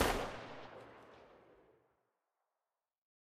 marlin_veryfar.ogg